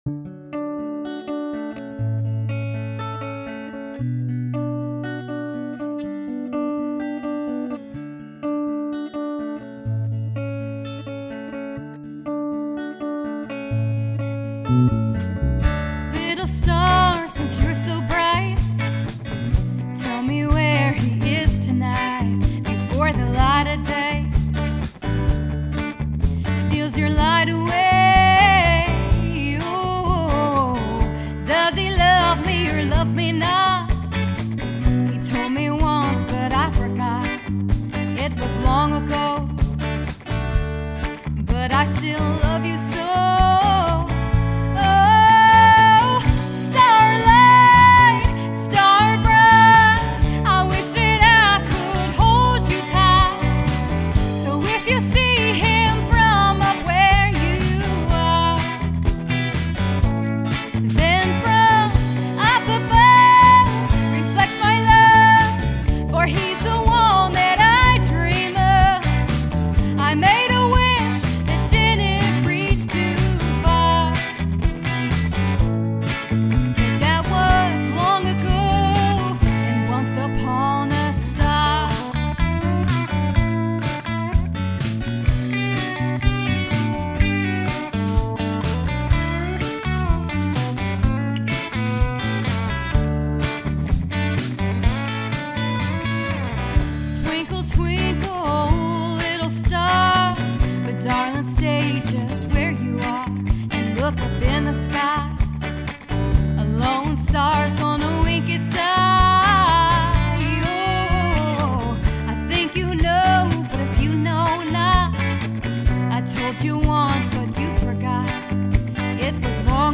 Listen to the band and me below.
band version